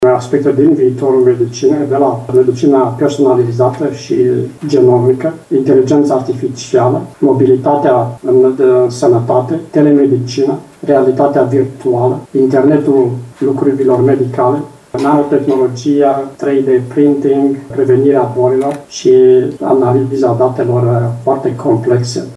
în deschiderea lucrărilor